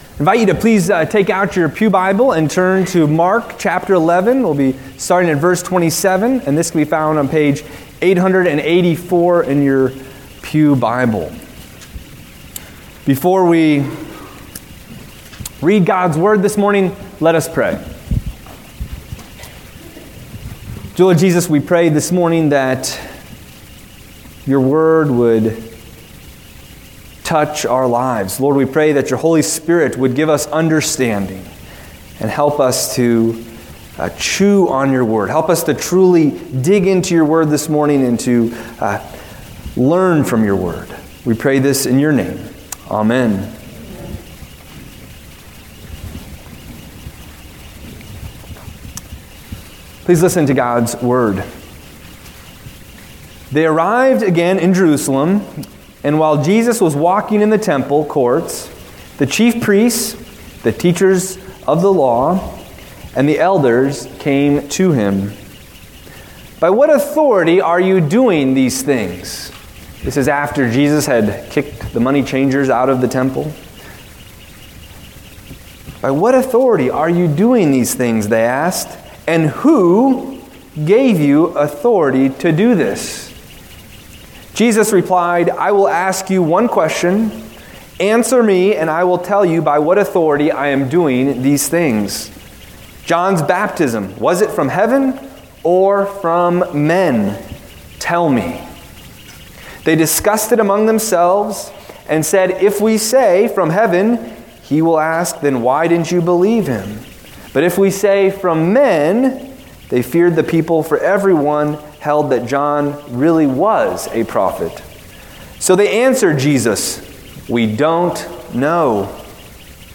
Service Type: Lent